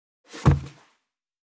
236,机に物を置く,テーブル等に物を置く,食器,グラス,コップ,工具,小物,雑貨,コトン,トン,ゴト,